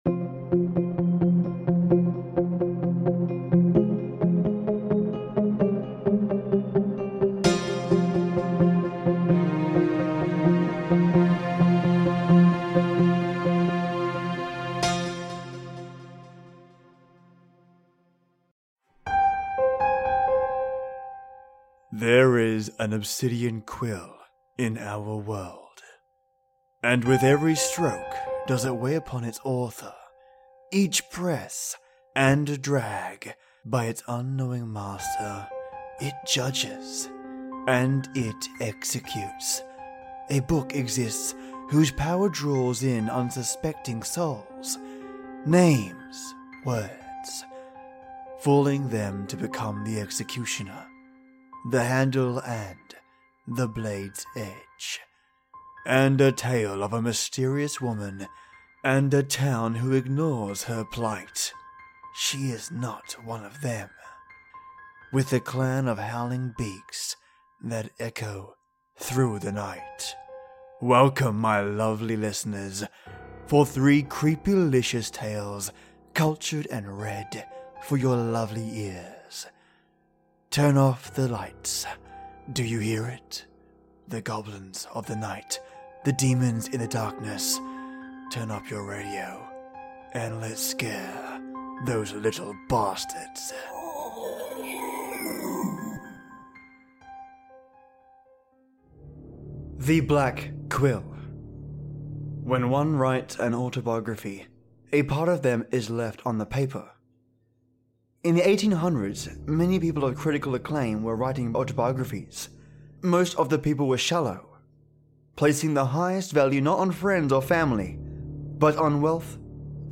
Obsidian Quill, Book of Names, and Howling Beaks | Creepy stories!
Welcome my lovely listeners, for three creepylicious tales, cultured and read for your lovely ears.